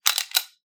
gun_slide_lock_4.ogg